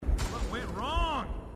Halo Dialogue Snippets